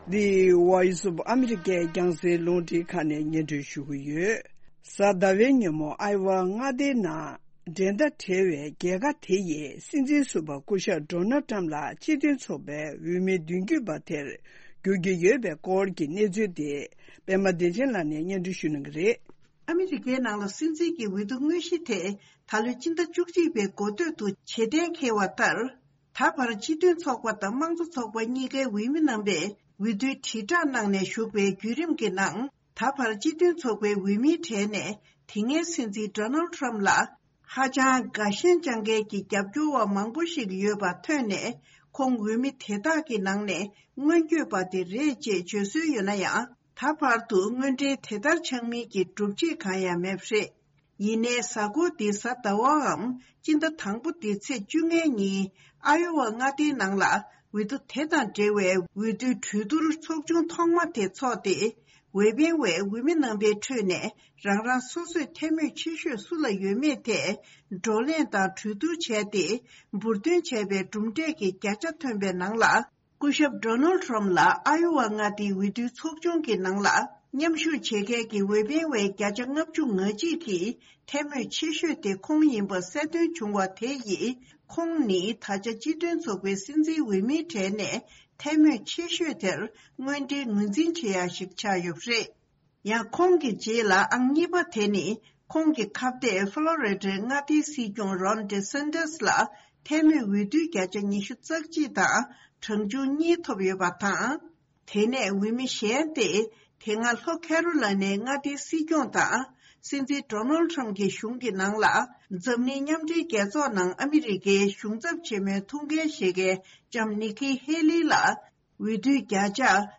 སྙན་སྒྲོན་རྗེས་གླེང་མོལ།